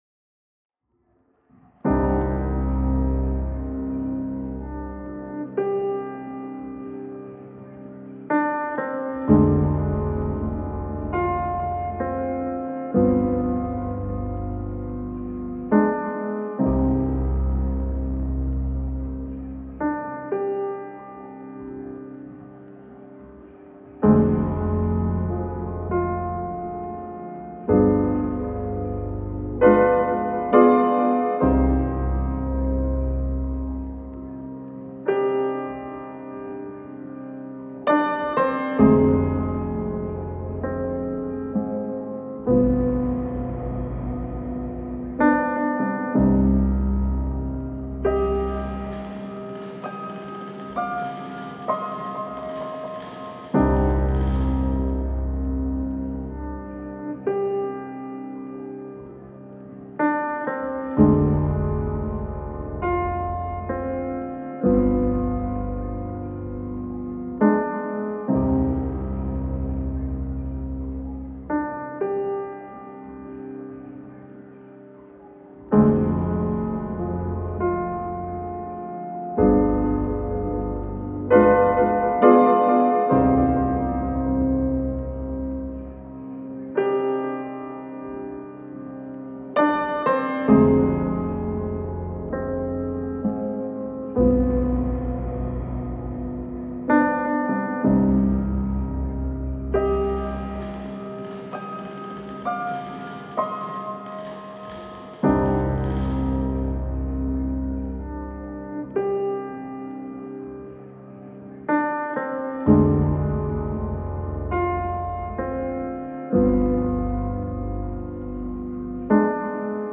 Melancholy and tragic film score with piano theme.